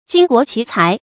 巾帼奇才 jīn guó qí cái
巾帼奇才发音